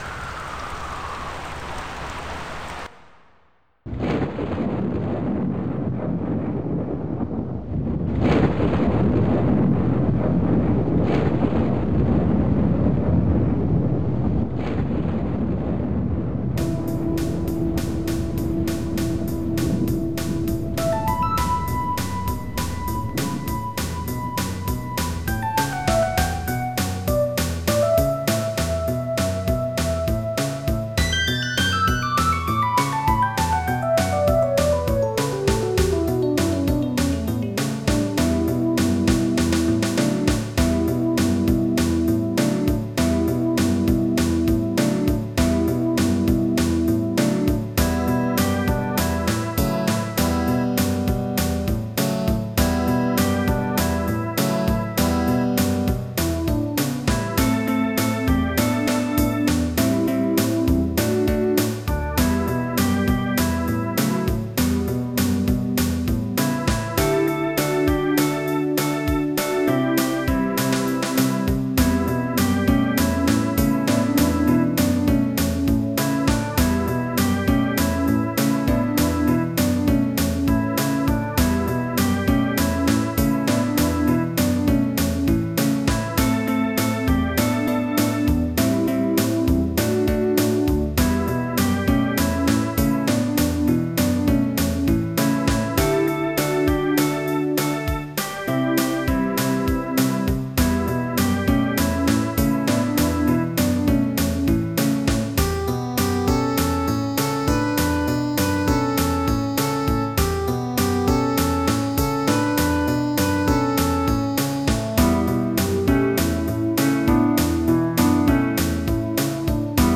MIDI Music File
Roland Type General MIDI